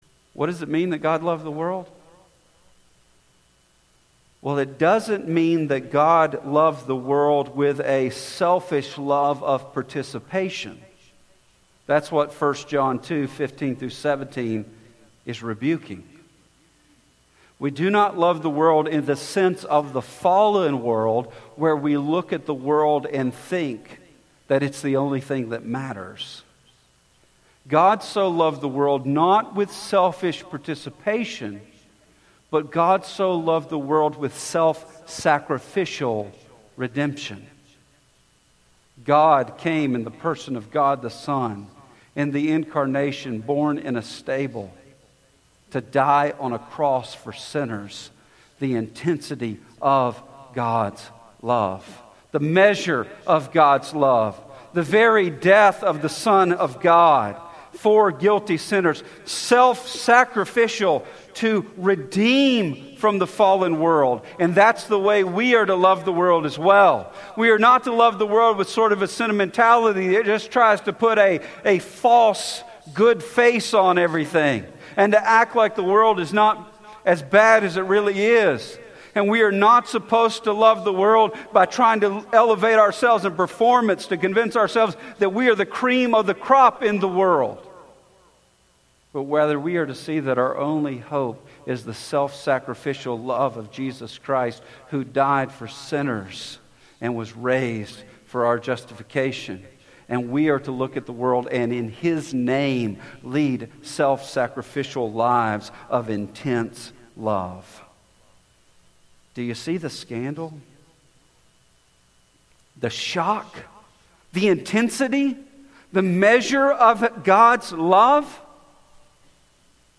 Sermon Excerpt